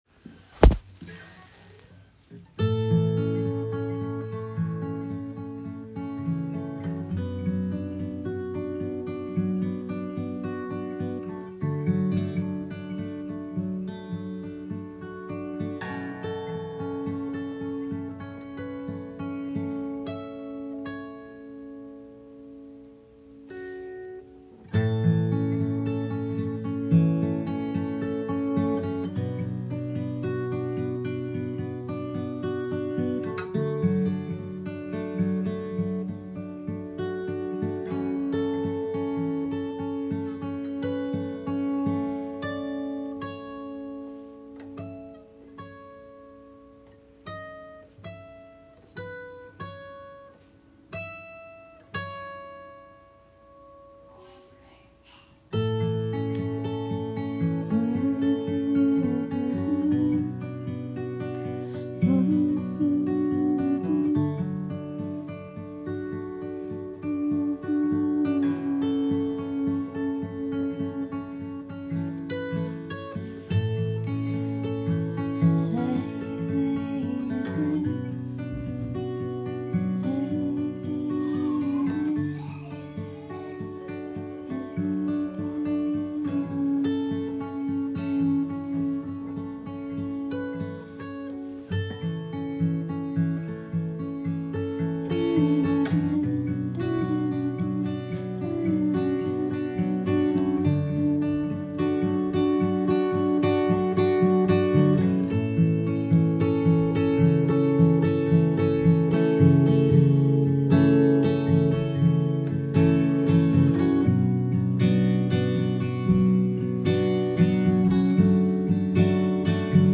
iphone recording, songwriting happening, unfinished. written may 2023. (kids in the background) humming. can’t get in the water.